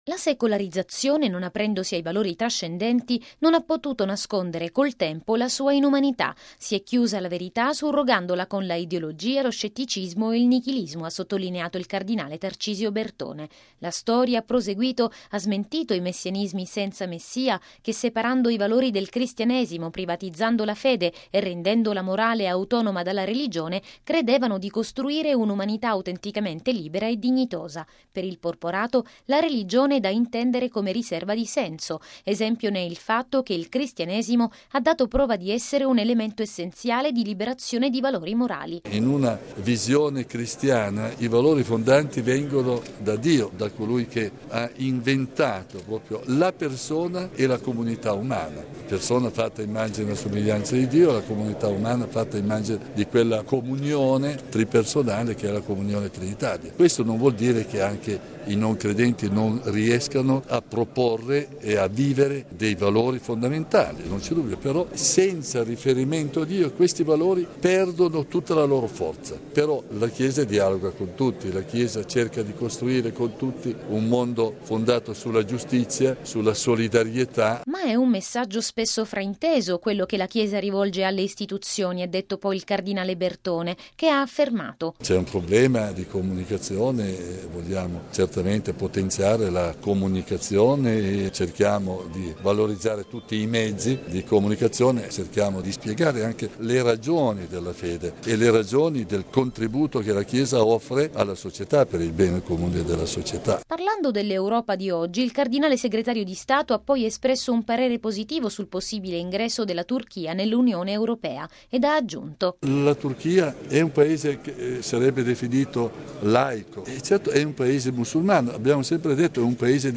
◊   “I cristiani sono l’avanguardia di una nuova Europa, che può essere realistica ma non cinica, ricca di ideali, libera da ingenue illusioni e ispirata alla verità del Vangelo”: è quanto ha detto il cardinale segretario di Stato, Tarcisio Bertone, intervenuto ieri pomeriggio a Roma al convegno “Cristianesimo e secolarizzazione. Sfide per la Chiesa e per l’Europa”.